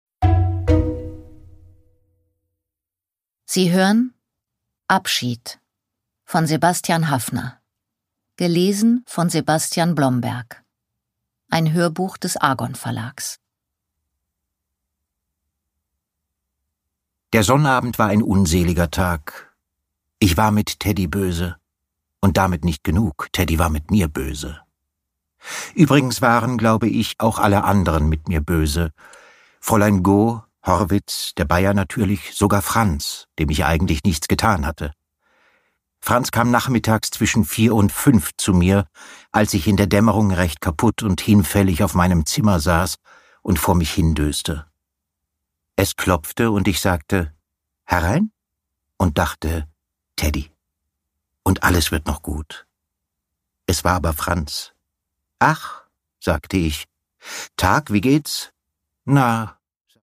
Produkttyp: Hörbuch-Download
Gelesen von: Sebastian Blomberg